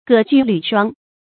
葛屦履霜 gě jù lǚ shuāng
葛屦履霜发音